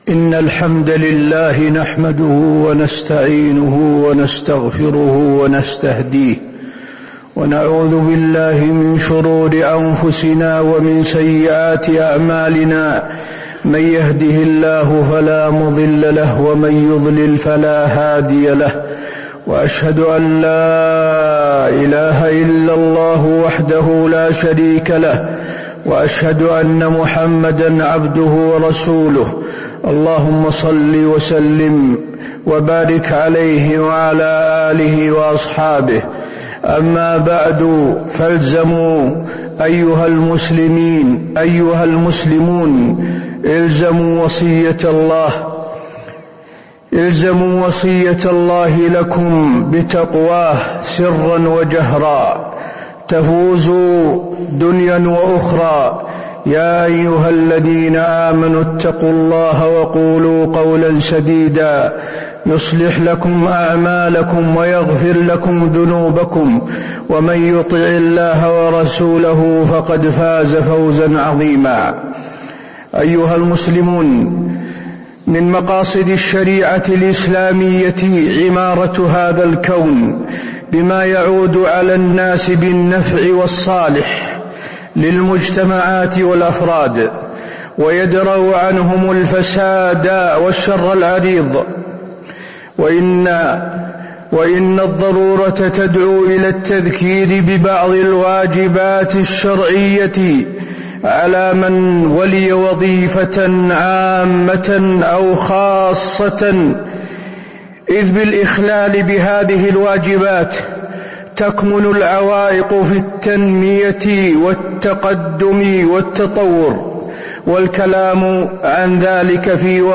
تاريخ النشر ٢٧ جمادى الآخرة ١٤٤٤ هـ المكان: المسجد النبوي الشيخ: فضيلة الشيخ د. حسين بن عبدالعزيز آل الشيخ فضيلة الشيخ د. حسين بن عبدالعزيز آل الشيخ وقفات مهمة للموظفين